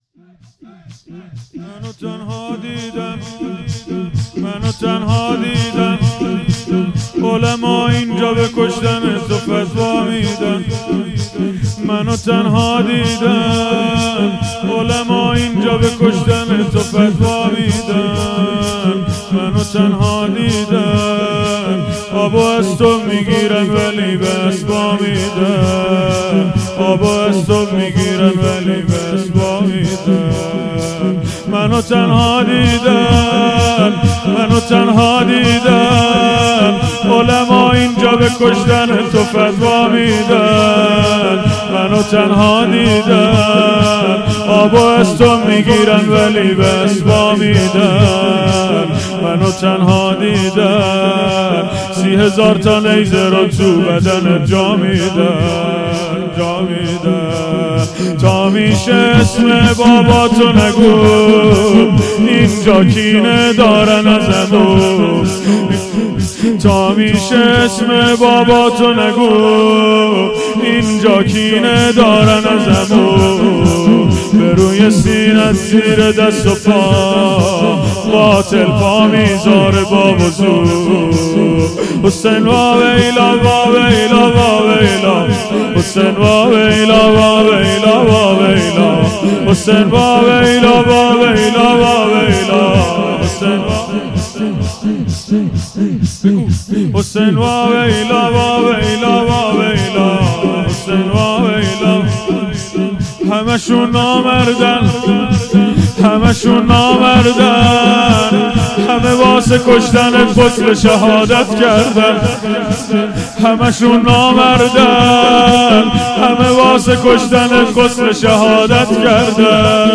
هیئت حسن جان(ع) اهواز - شور روضه ای
دهه اول محرم الحرام ۱۴۴۴